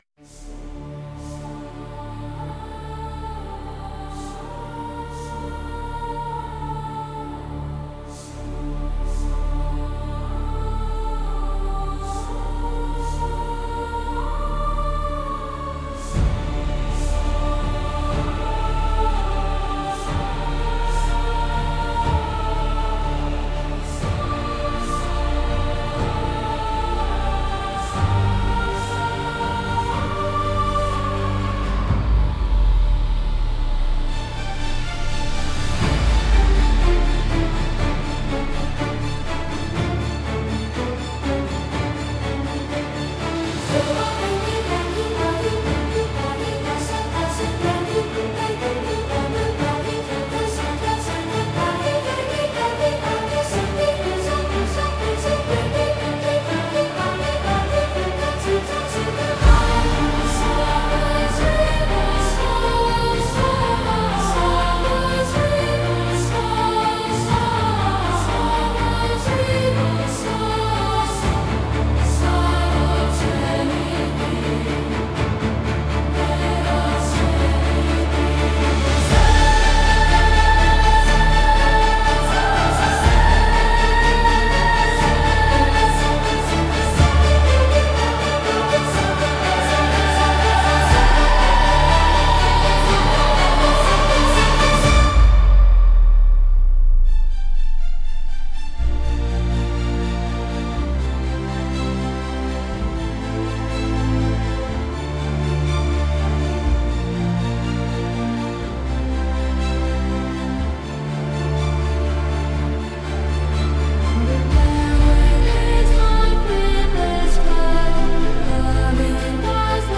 Pour le livre audio, la musique d’accompagnement est donc une fugue, une reprise de la célèbre « Toccata » de Jean Sébastien Bach.